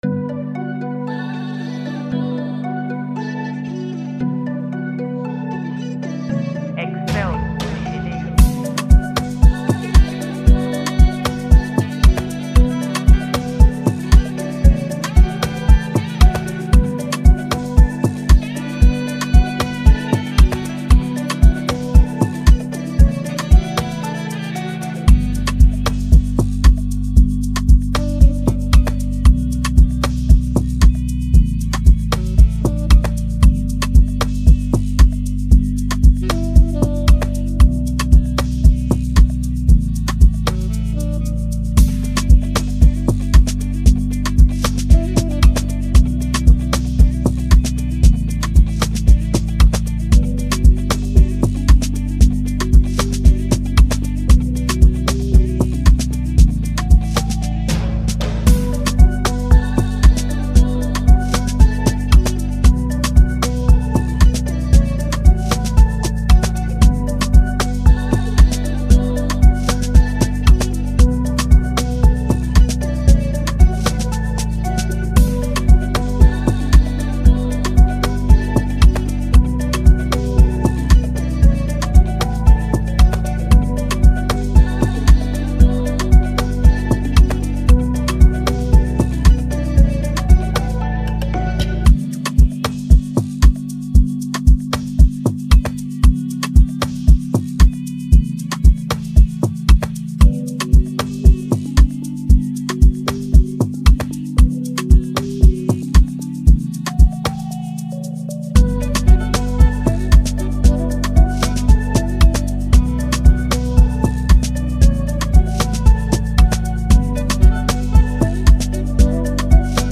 catchy, dance-worthy beat